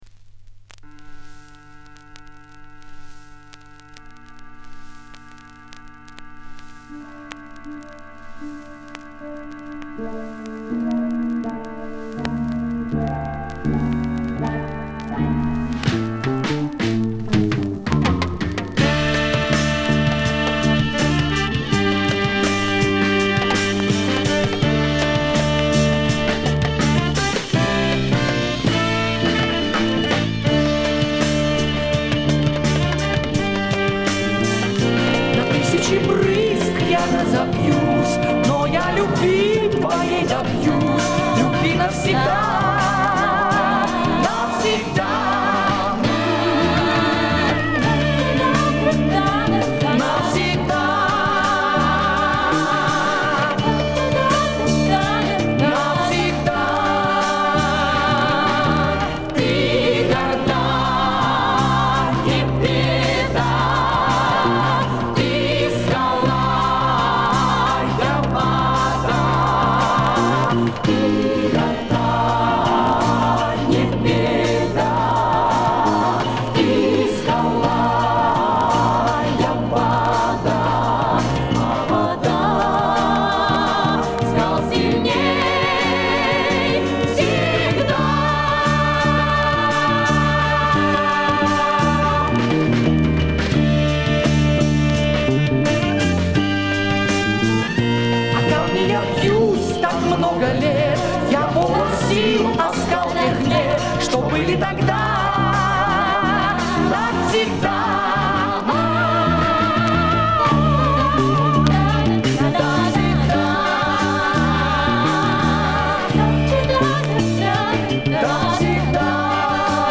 Жанр: Советская эстрада
Хруст иголки порадовал
Специально оставил похрустывание,ностальгия...